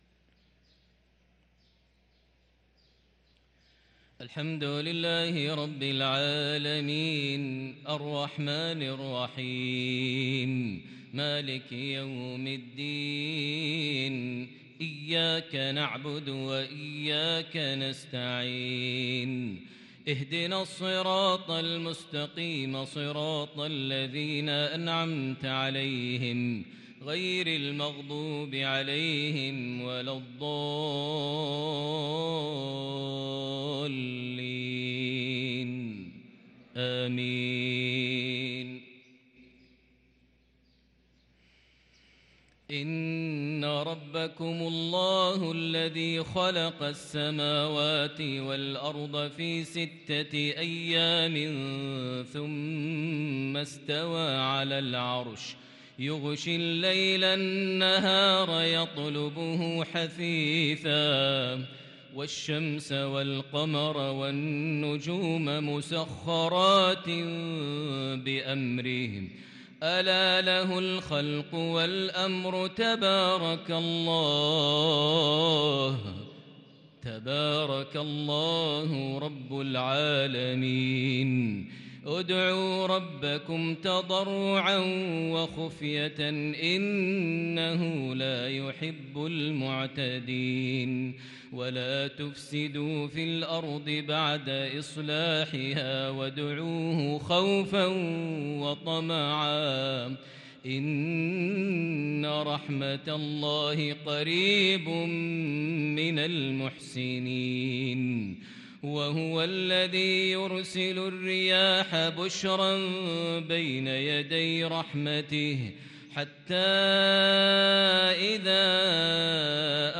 صلاة المغرب للقارئ ماهر المعيقلي 11 جمادي الآخر 1444 هـ
تِلَاوَات الْحَرَمَيْن .